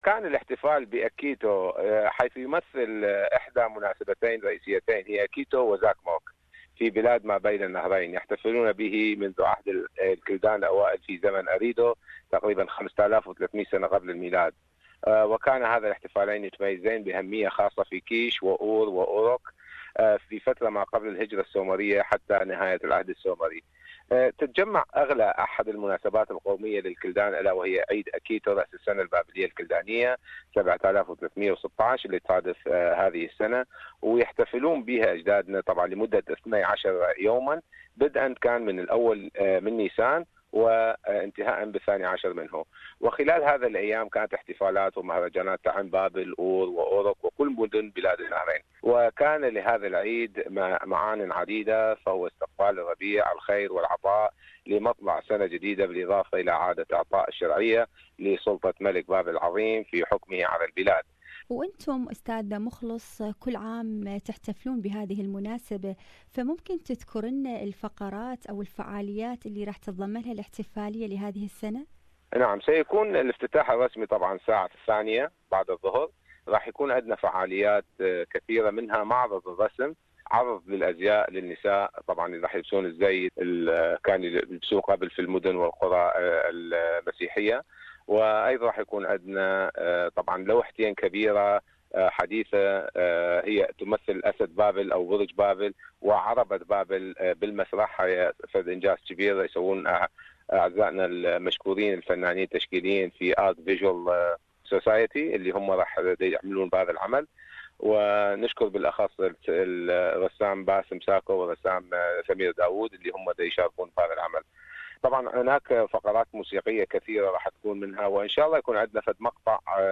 The Australian Chaldean Federation - Victoria will celebrate on Sunday the 20th of March the Chaldean Babylonian New Year 7316 K. More about this issue, listen to this interview